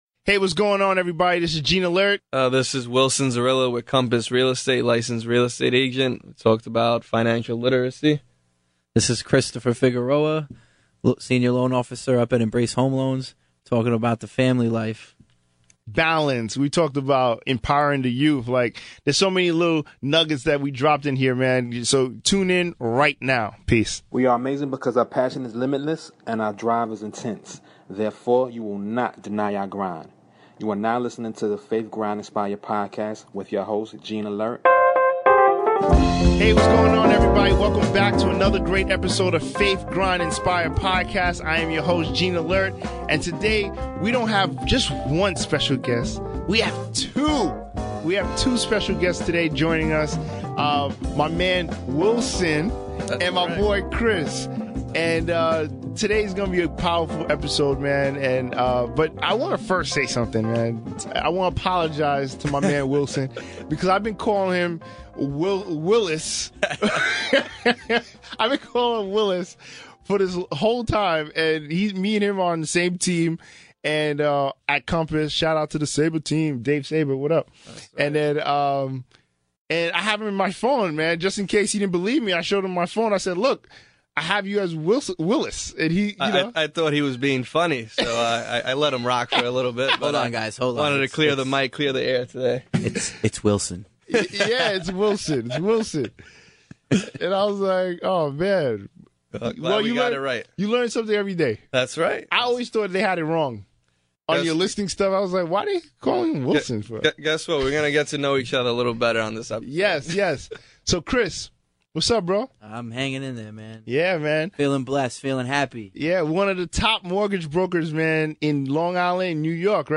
Strong Relationships are Key to Thrive - A conversation